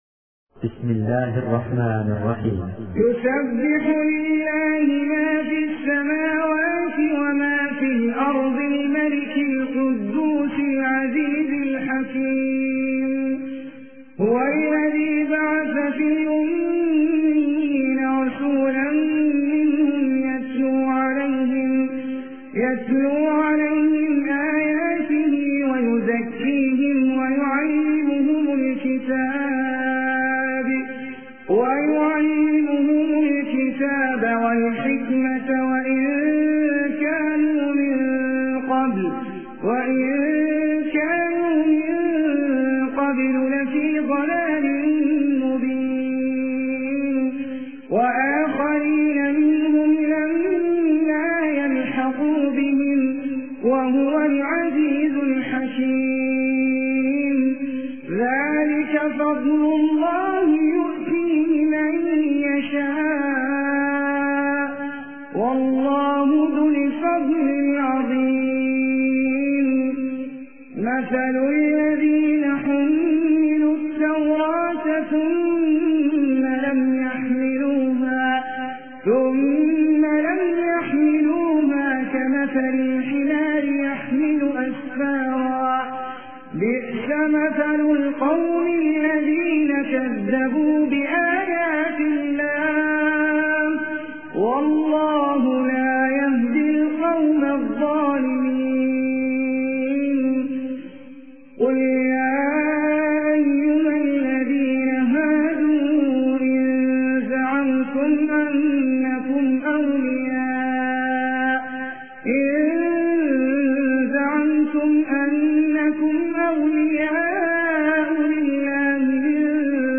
Quran Recitation
Recitation By Ahmad Ali Al Ajmi